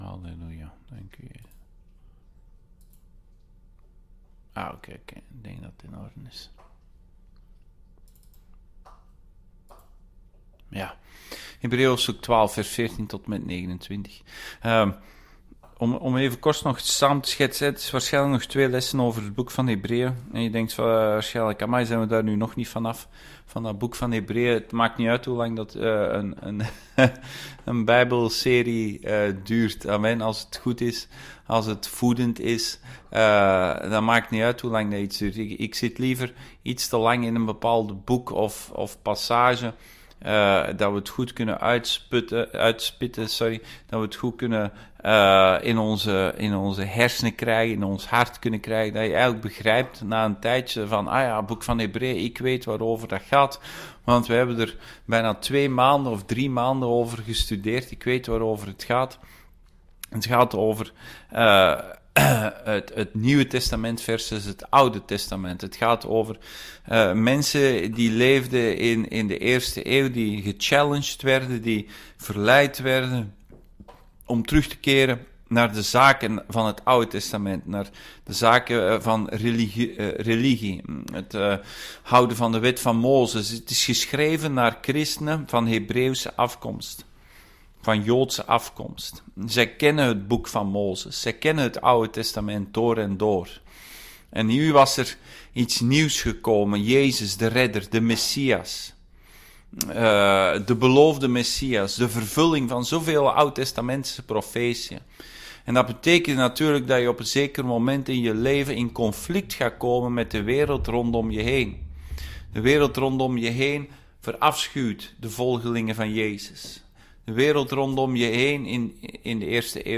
Hebreeën Dienstsoort: Bijbelstudie « Een vraag van de Heer